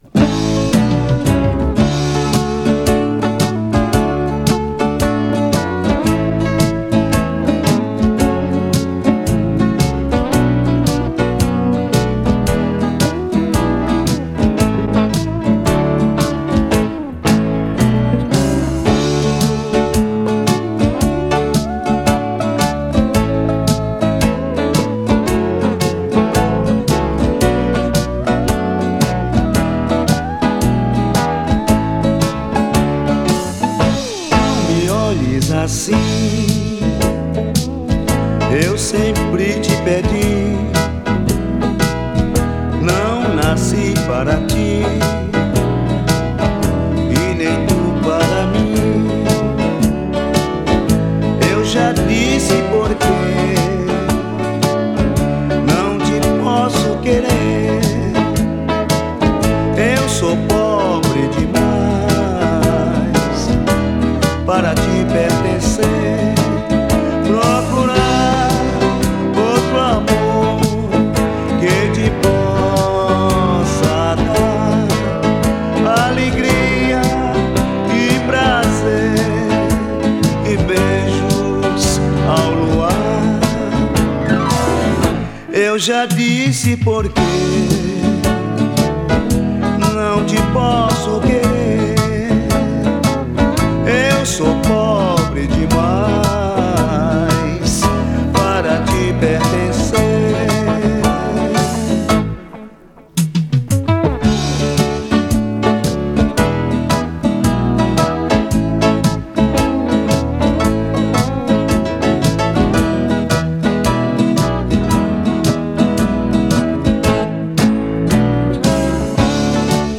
2087   08:05:00   Faixa:     Bolero